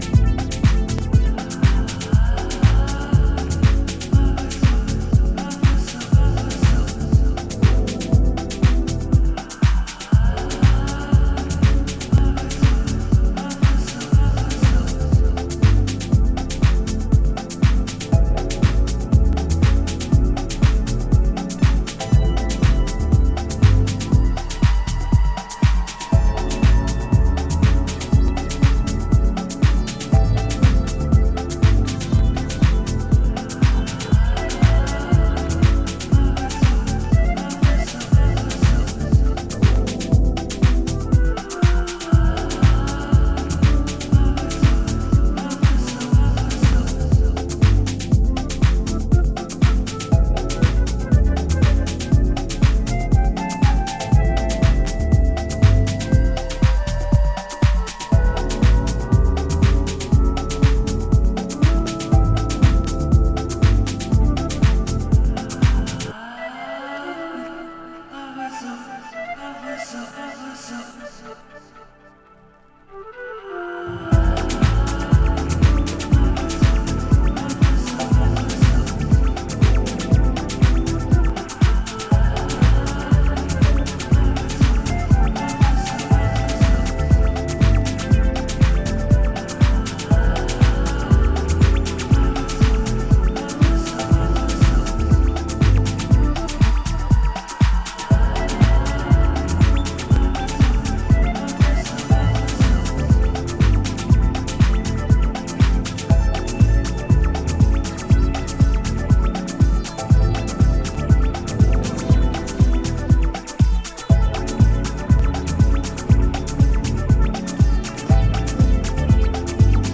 柔らかい浮遊コードやスペーシーなシンセを駆使しながら、じっくりとビートダウン・ハウスを繰り広げています。